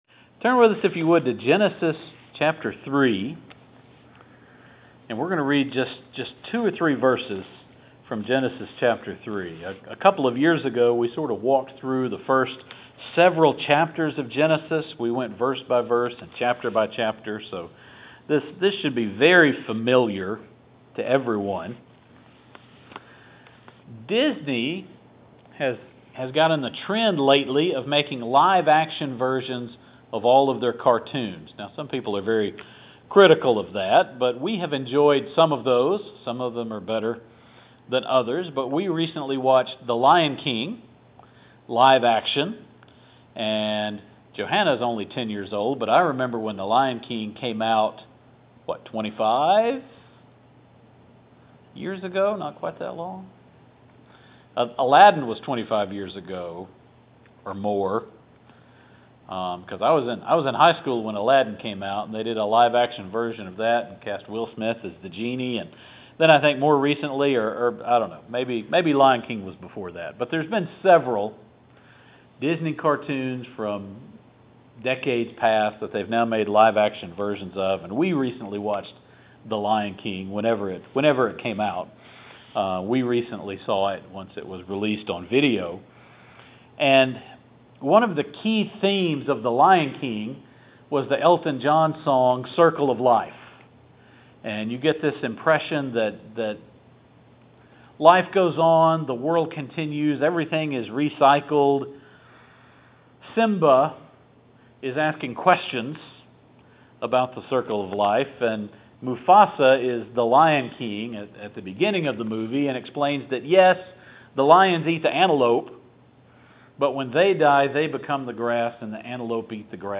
Adam was made by God in his own image, then God breathed into him the breath of life. In the New Covenant we posses a great treasure (the indwelling Holy Spirit) in jars of clay. I wish had explained the sermon title this well on Sunday morning.